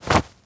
foley_cloth_light_fast_movement_12.wav